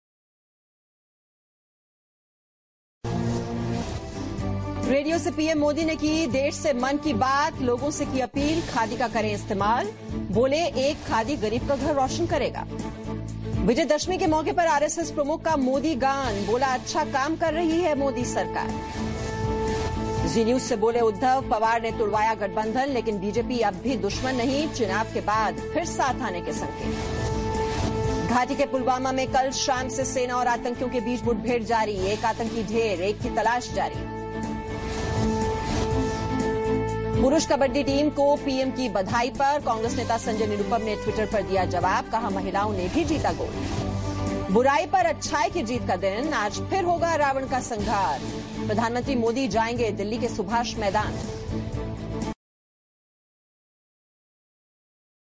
Headlines at 2.30 pm